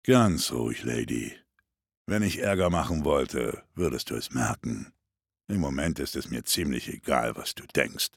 The localised version features the well-known German dubbing voices of the 5 protagonists from the ”Shadows of Evil” zombie mode.